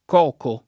-chi — pop. tosc. coco [
k0ko]: Sanno farla da eroe come da coco [S#nno f#rla da er0e k1me dda kk0ko] (Giusti) — dim. o vezz.